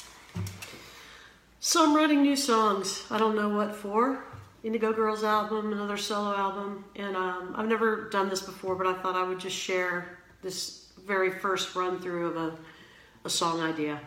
(recorded from facebook)